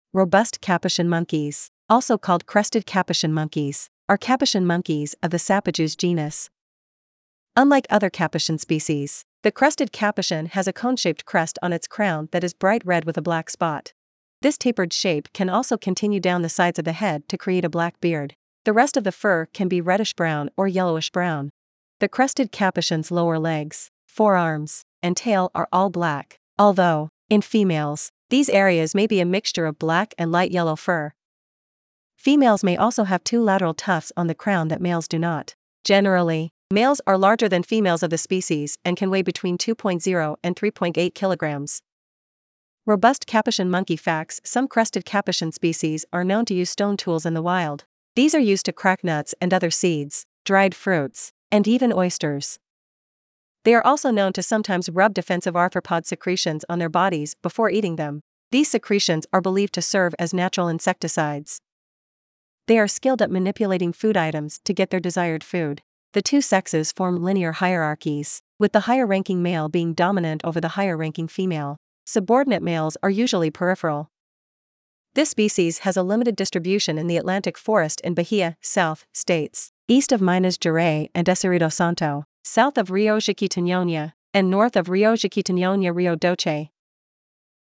Robust Capuchin Monkey
Robust-Capuchin-Monkey.mp3